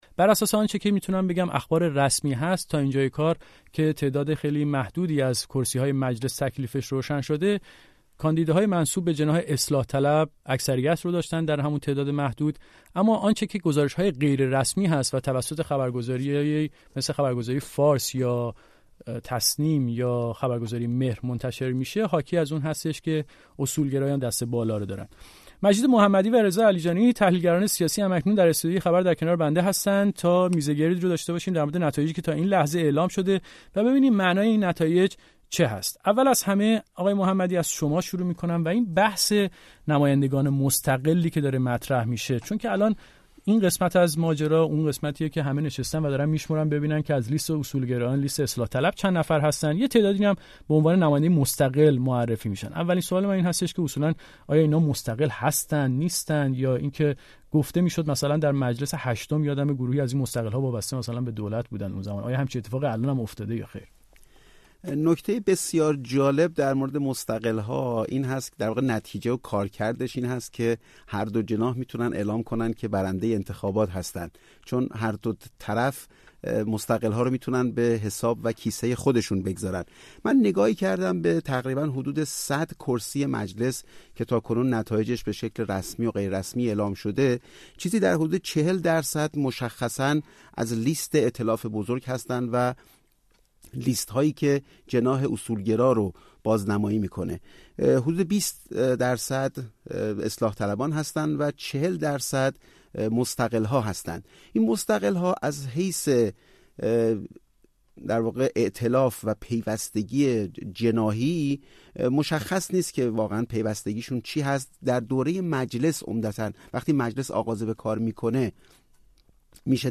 هر دو در استودیوی رادیو فردا در پراگ.